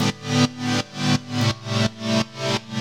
GnS_Pad-MiscA1:4_170-A.wav